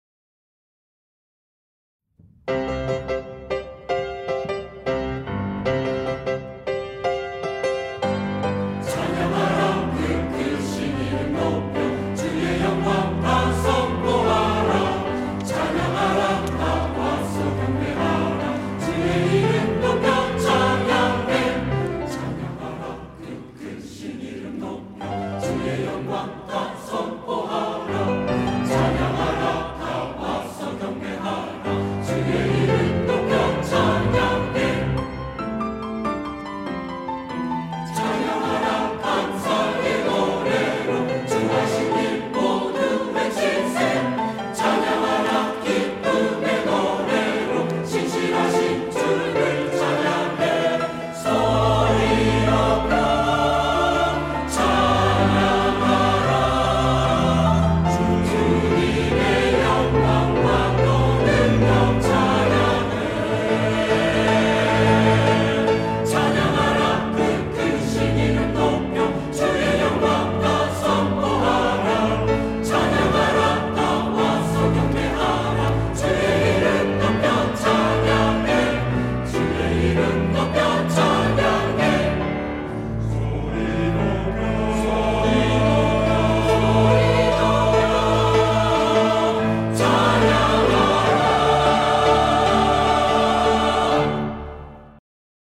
시온(주일1부) - 찬양하라 그 크신 이름 높여
찬양대